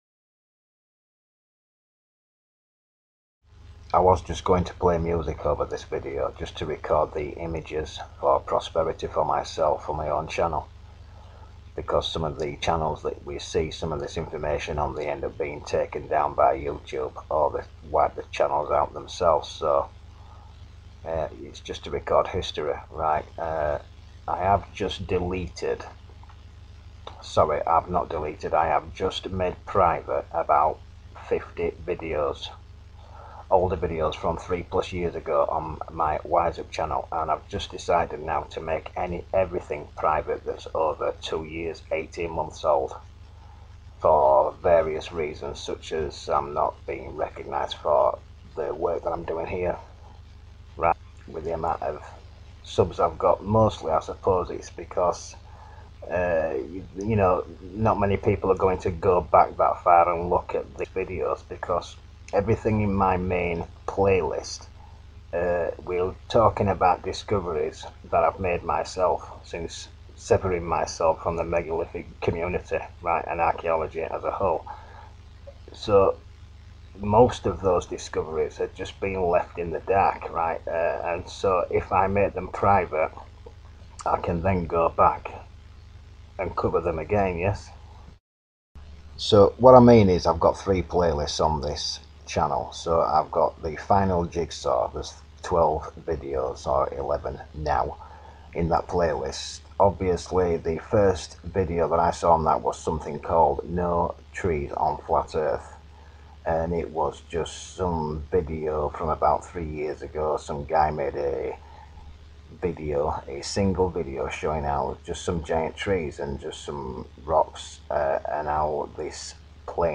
cOz ........ forgot to pull fan out the fan whilst recording so you can probably hear it, anyway its not too important here